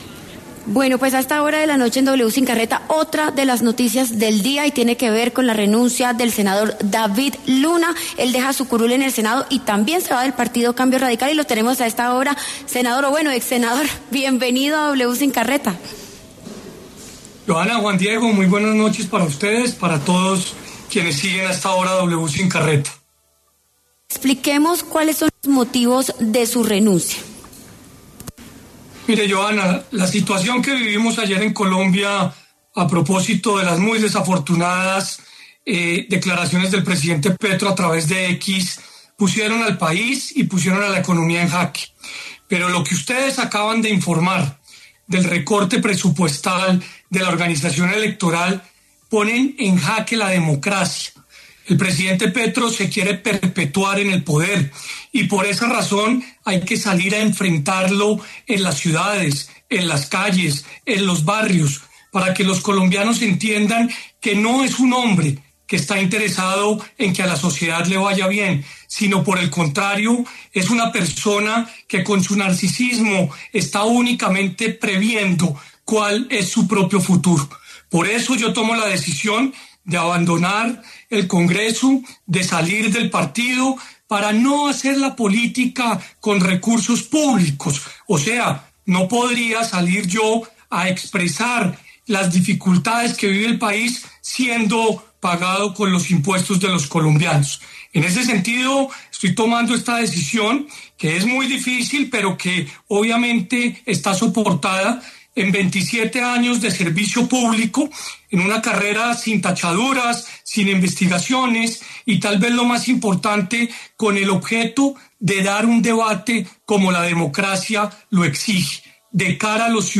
Por esta razón, el mismo David Luna habló en los micrófonos de W Sin Carreta para explicar cuáles fueron esas razones que lo llevaron a tomar la decisión de dejar su curul en la corporación.